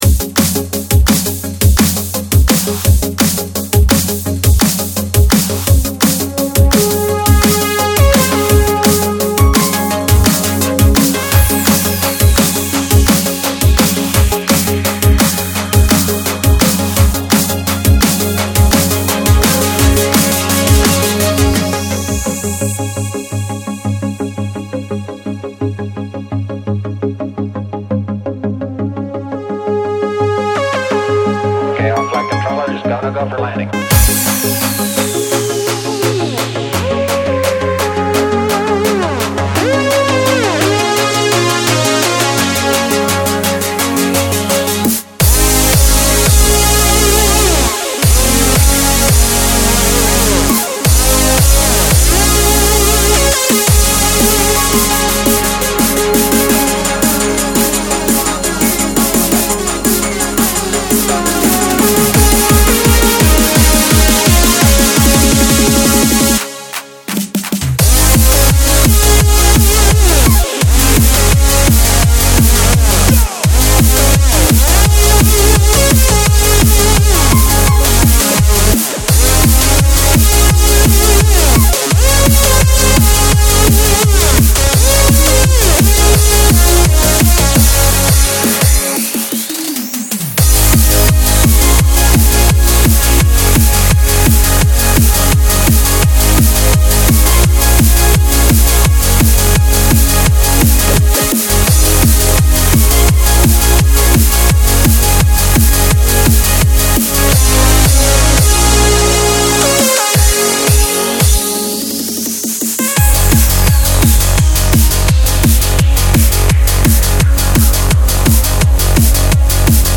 BPM170
MP3 QualityMusic Cut
Some happy hardcore by the happy hardcore queen herself